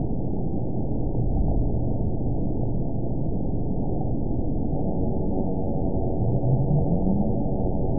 event 914318 date 05/05/22 time 00:50:12 GMT (3 years ago) score 9.30 location TSS-AB02 detected by nrw target species NRW annotations +NRW Spectrogram: Frequency (kHz) vs. Time (s) audio not available .wav